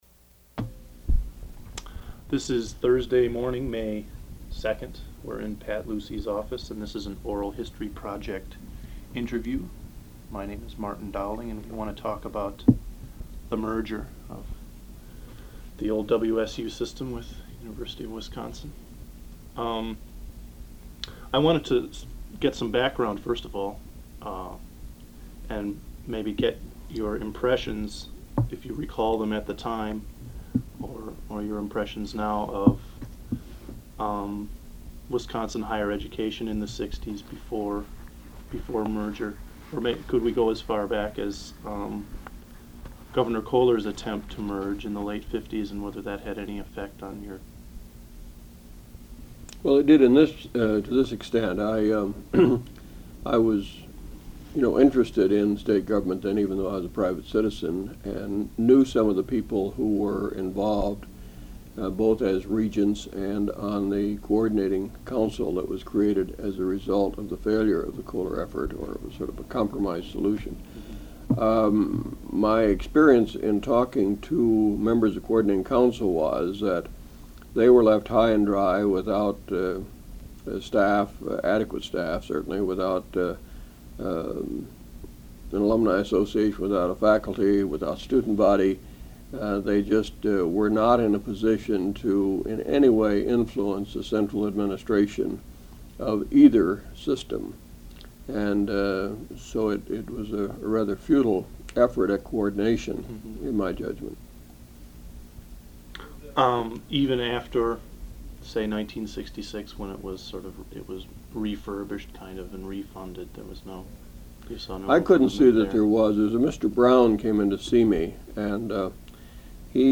Oral History Interview: Patrick J. Lucey (0306)